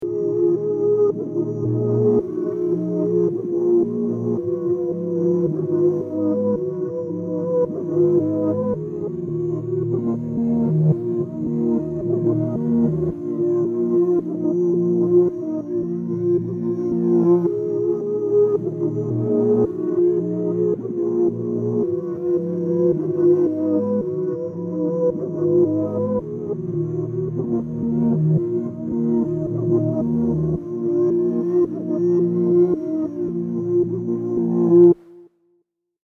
✅ Professionally Mixed